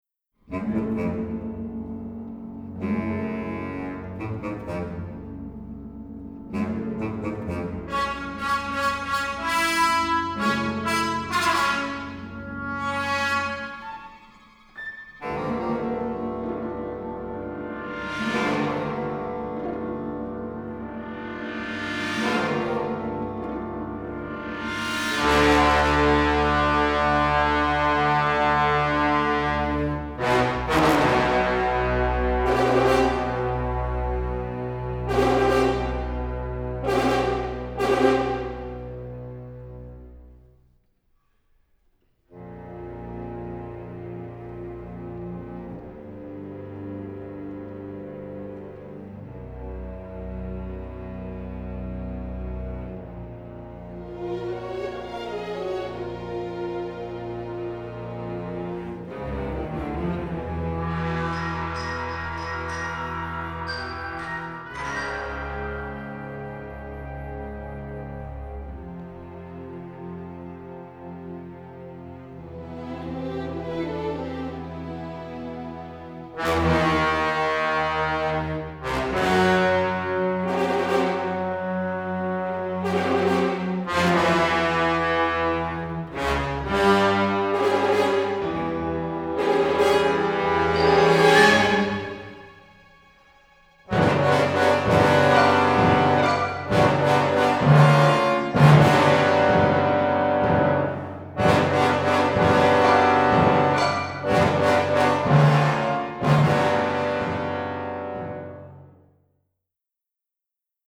crisp recording